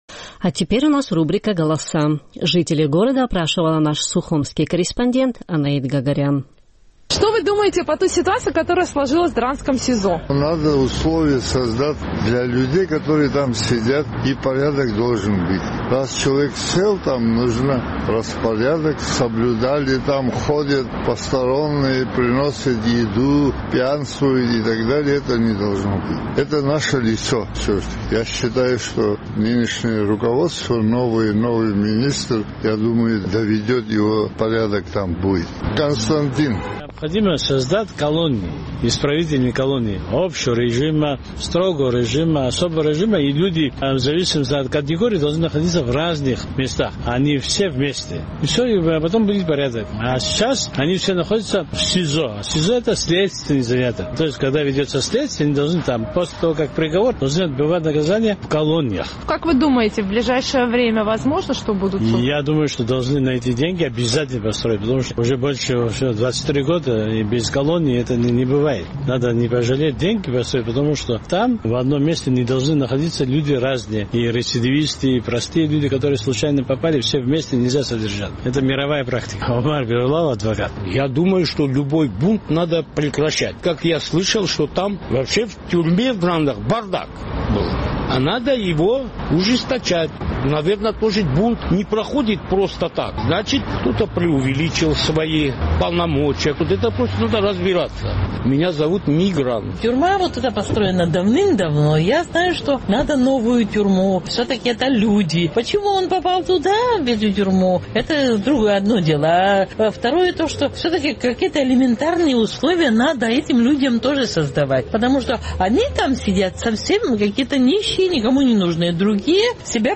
Наш сухумский корреспондент поинтересовалась у жителей абхазской столицы, что они думают о ситуации, сложившейся в драндском СИЗО.